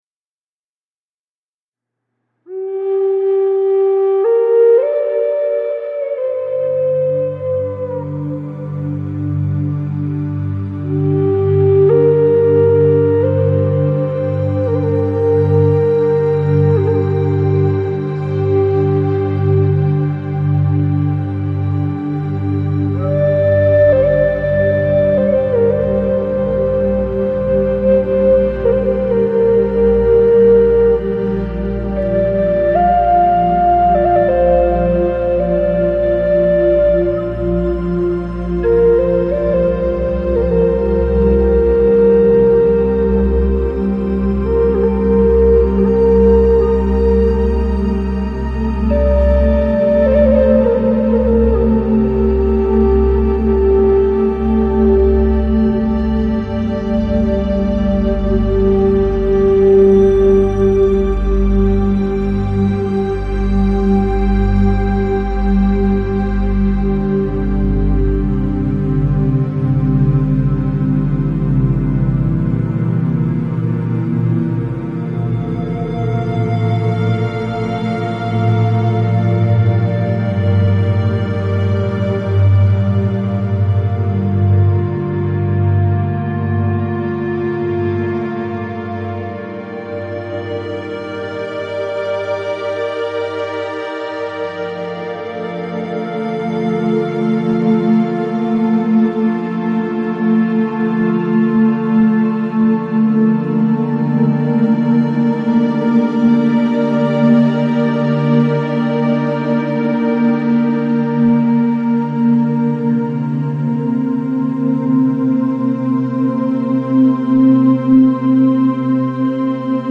Жанр: New Age | Classical